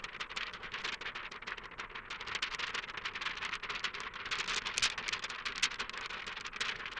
Synthesized examples twice the duration of the originals.
ShakingPaper
ShakingPaper.wav